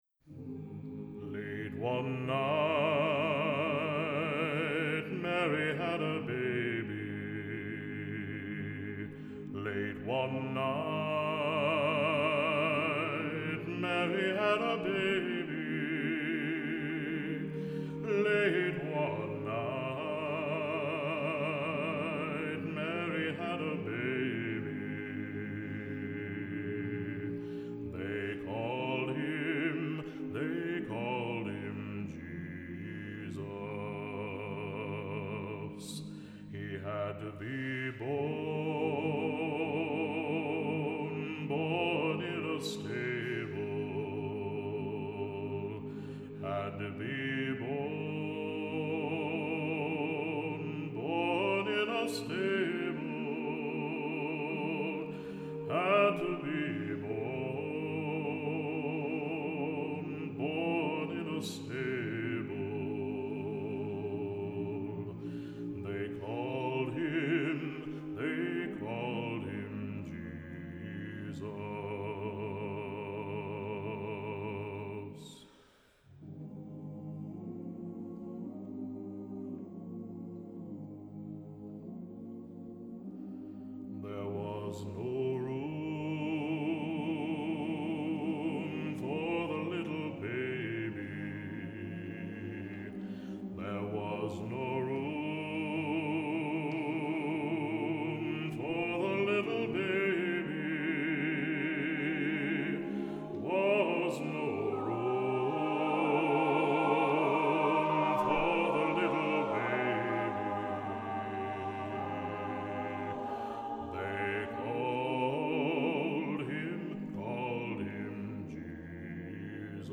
Voicing: SATB; Bass Solo